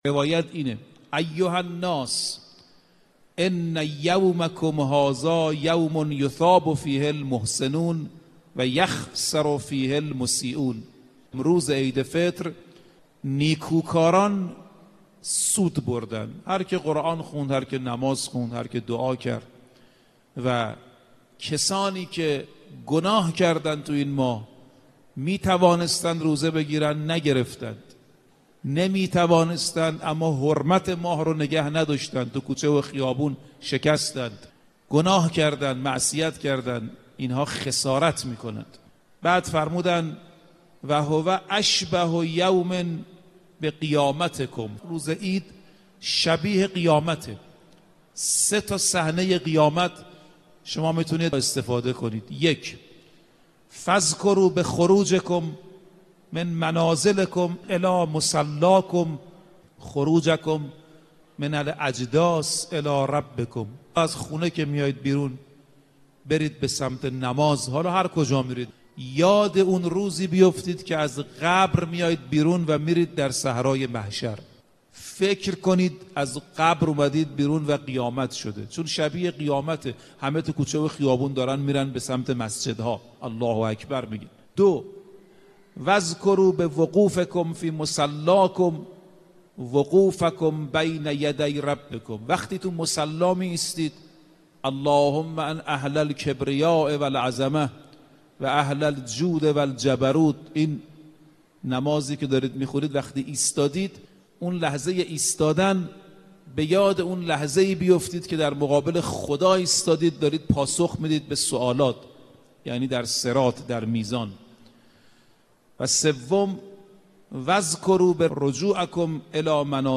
جایزه در روایات به مغفرت، بهشت و آمرزش تعبیر شده است. در ادامه بخشی از سخنرانی